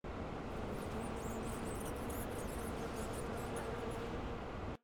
楼道场景1.ogg